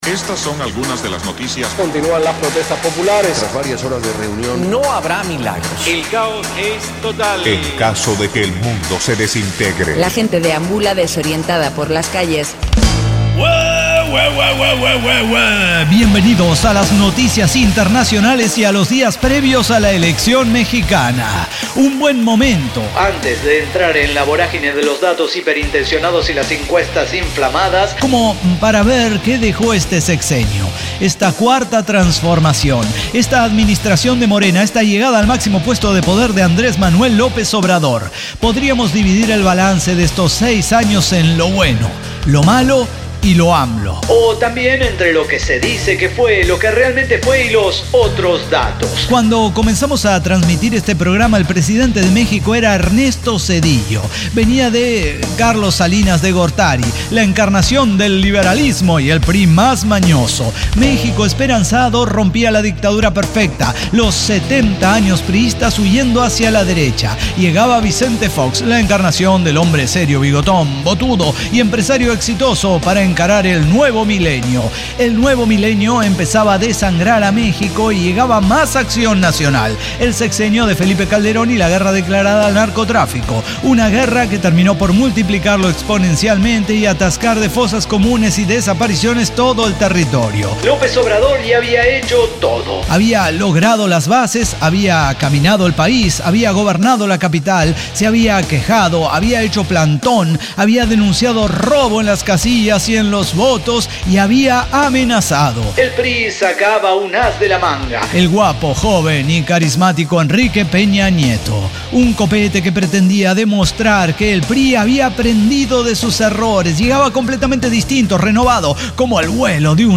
ECDQEMSD podcast El Cyber Talk Show – episodio 5786 Lo Bueno, Lo Malo Y Lo AMLO – balance de otro sexenio